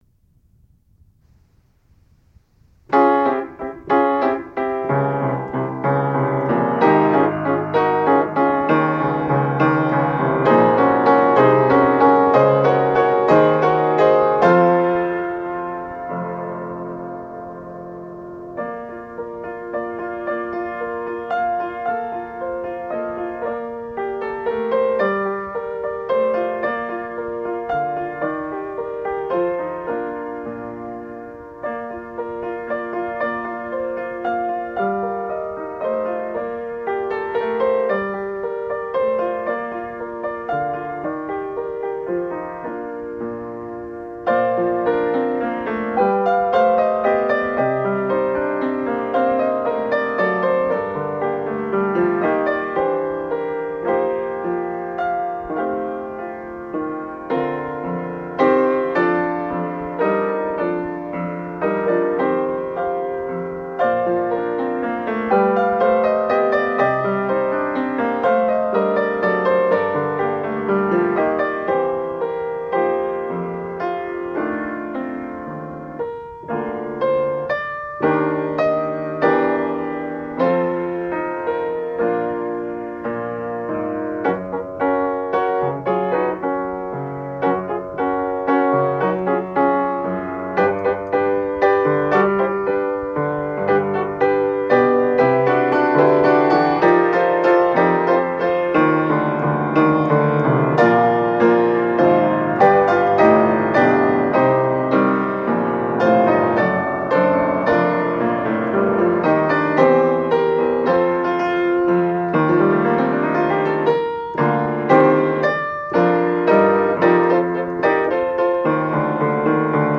Here are some of my favorite piano arrangements: O Come All Ye Faithful, Silent Night, While Shepherds Watched Their Flocks, Three Carols, Little Drummer Boy